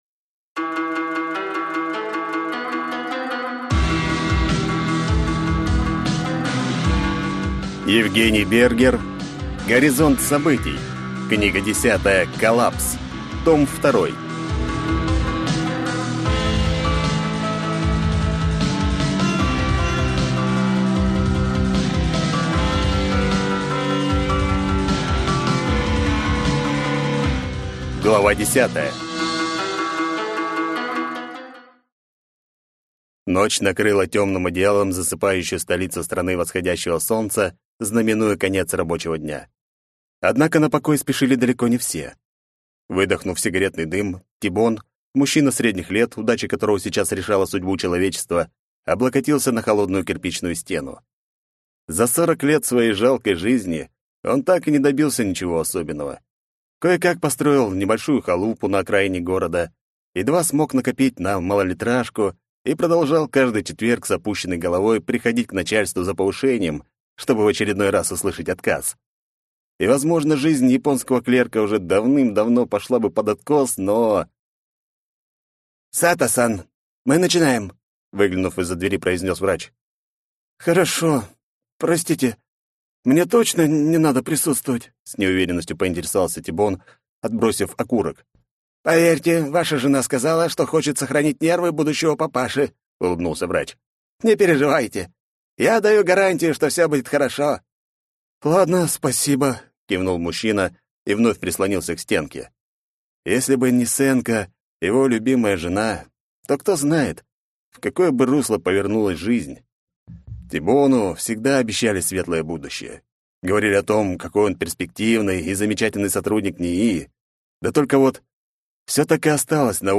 Аудиокнига Горизонт событий. Книга 10. Коллапс. Том 2 | Библиотека аудиокниг